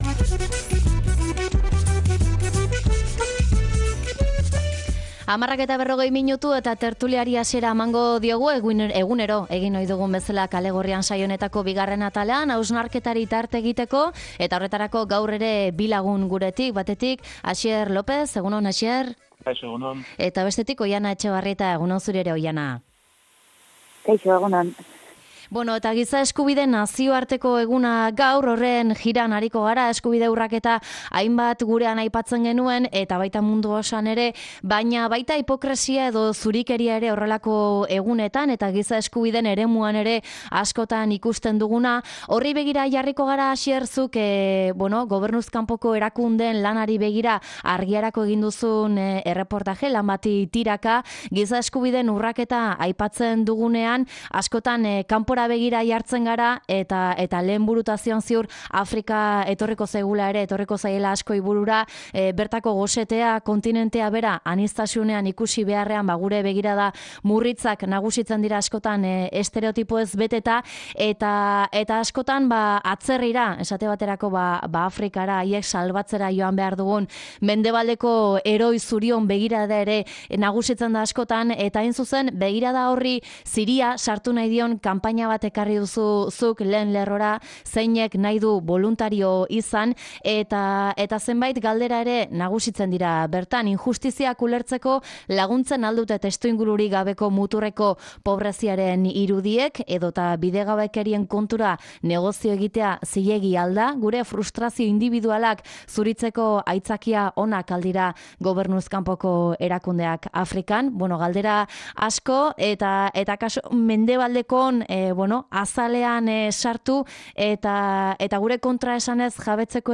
Tertulia: Giza Eskubideak, gordintasuna eta hipokresia